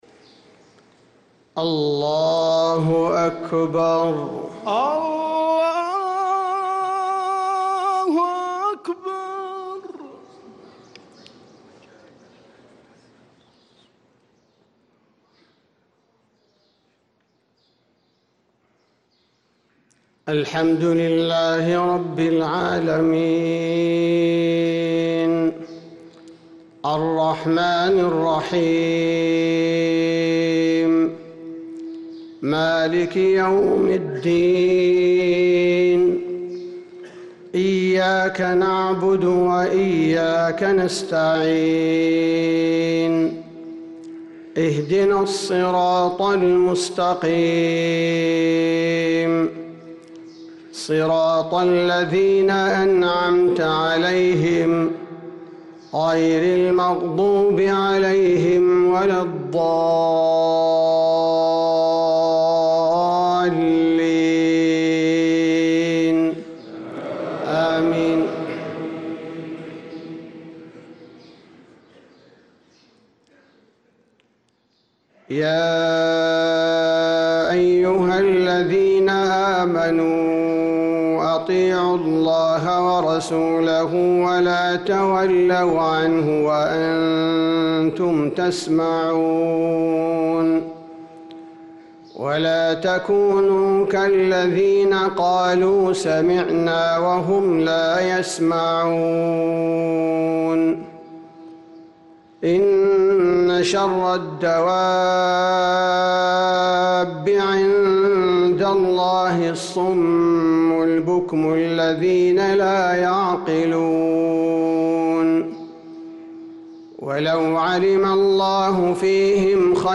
صلاة الفجر للقارئ عبدالباري الثبيتي 29 جمادي الأول 1446 هـ